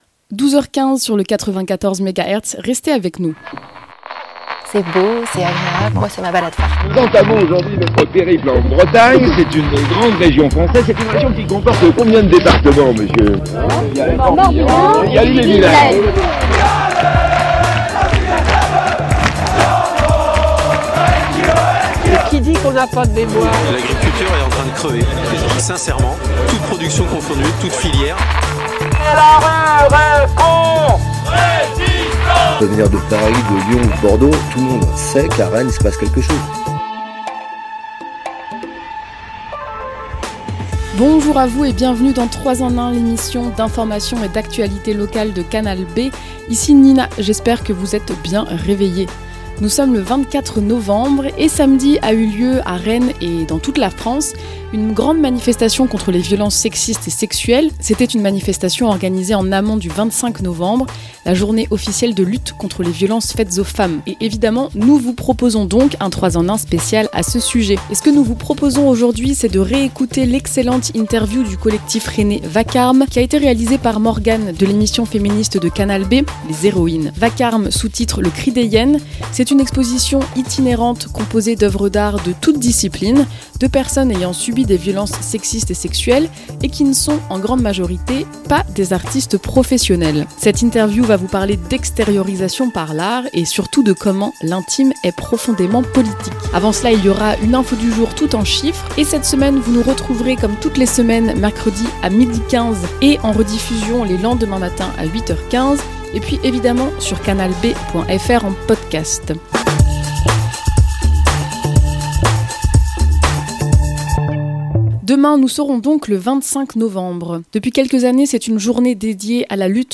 Émission spéciale de 3 EN 1 à l'occasion de la journée internationale de lutte contre les violences faites aux femmes, ce mardi 25 novembre